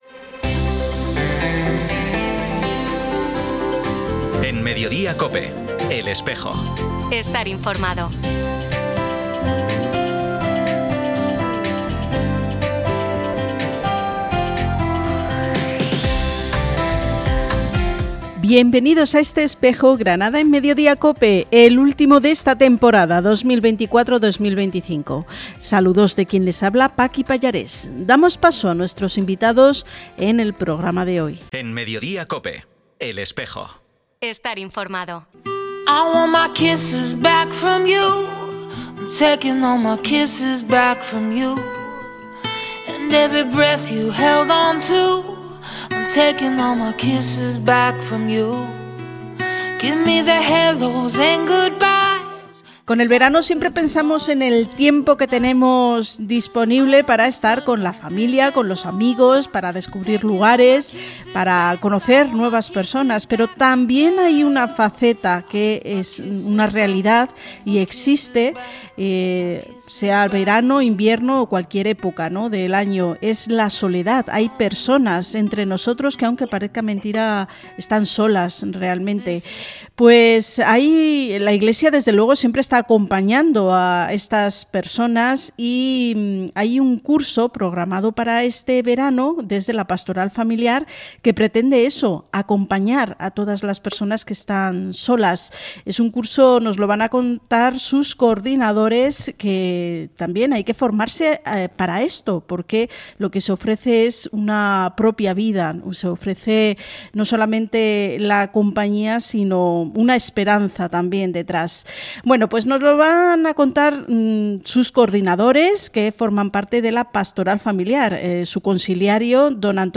Programa emitido en COPE Granada y COPE Motril, el 27 de junio de 2025.